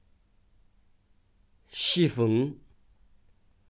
05湿风